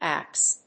発音記号・読み方
/æps(米国英語)/